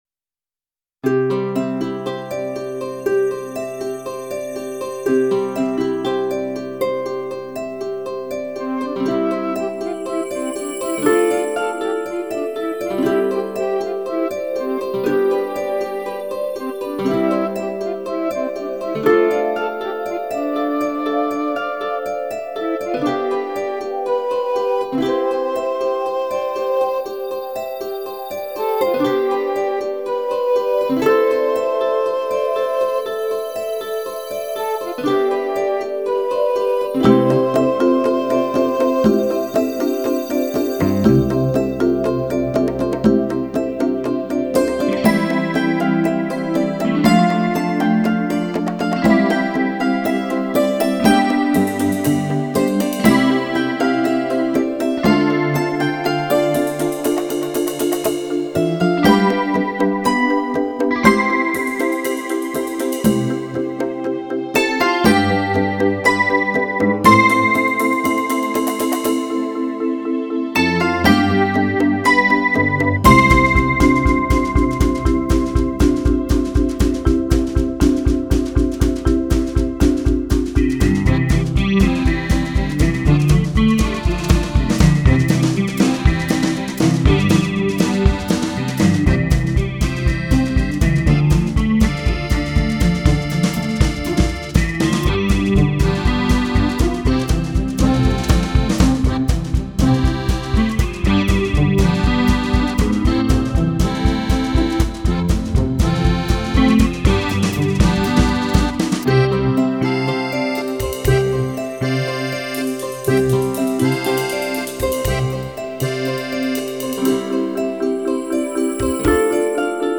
Backing track.